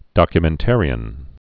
(dŏkyə-mĕn-târē-ən, -mən-) also doc·u·men·ta·rist (dŏkyə-mĕntər-ĭst)